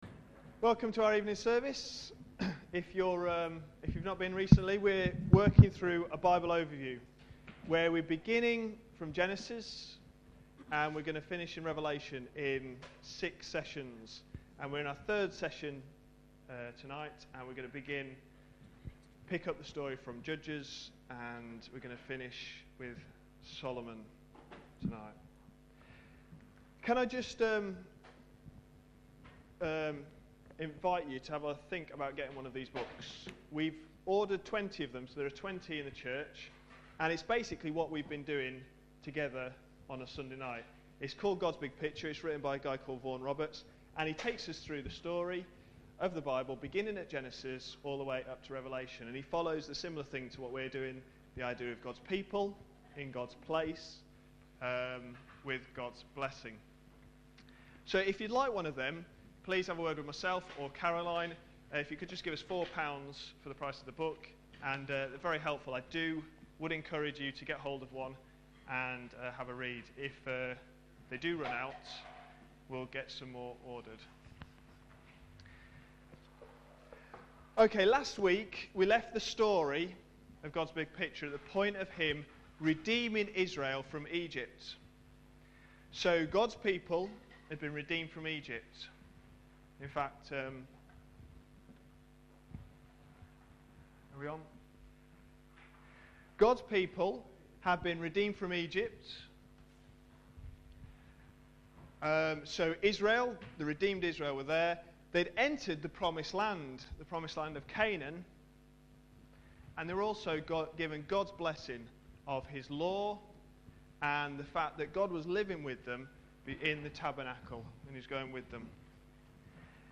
A sermon preached on 17th October, 2010, as part of our God's Big Picture series.